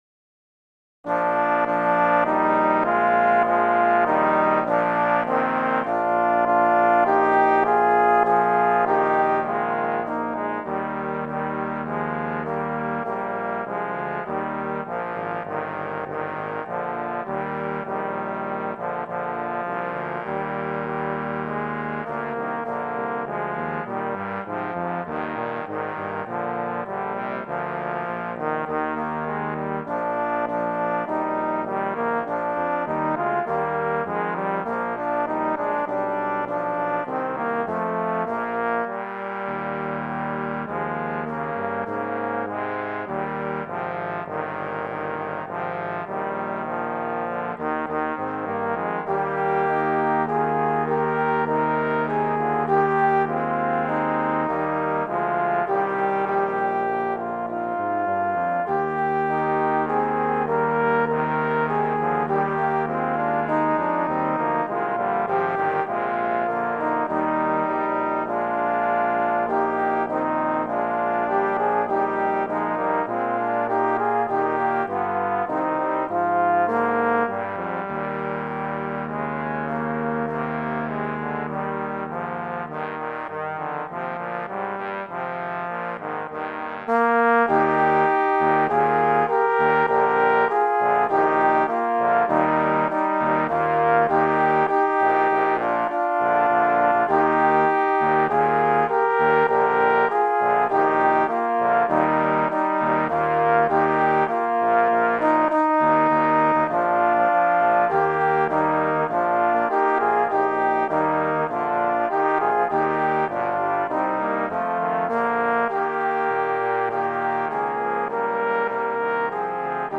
Voicing: Trombone Quartet